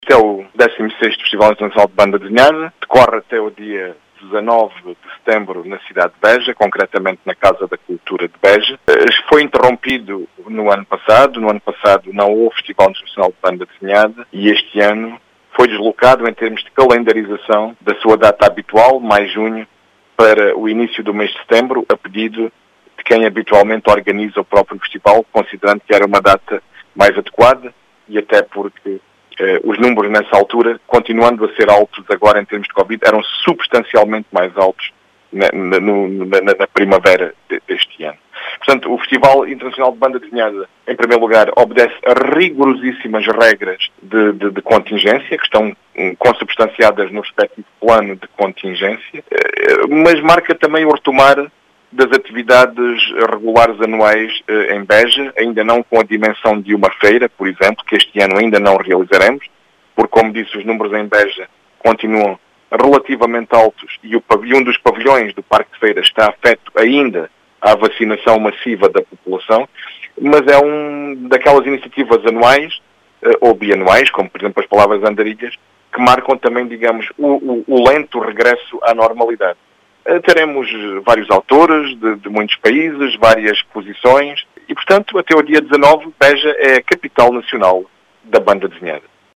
As explicações são do presidente da Câmara Municipal de Beja, que fala numa iniciativa que marca o “lento regresso à normalidade” das actividades em Beja, que por estes dias será, a “capital nacional da Banda Desenhada”.